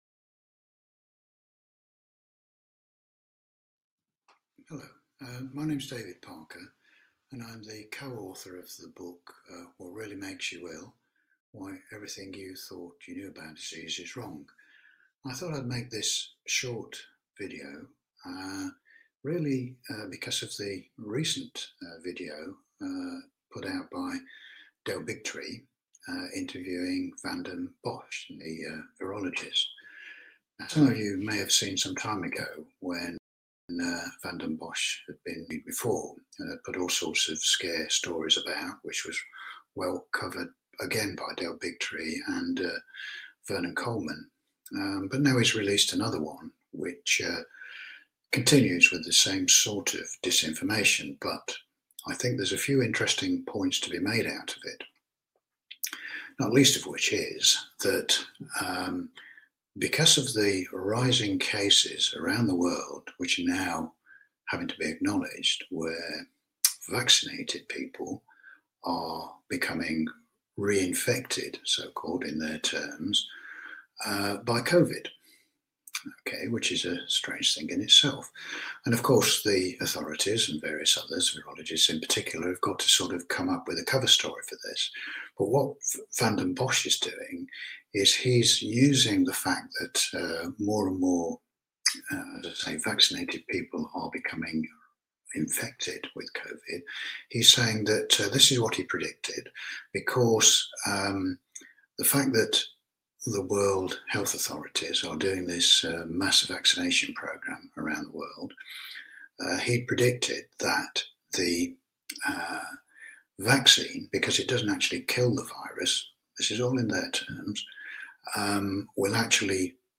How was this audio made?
Permanent Link URL Friendly (SEO) Current Time (SEO) Category: Live Stream You must login to be able to comment on videos Login Load More